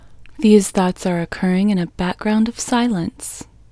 LOCATE Short IN English Female 6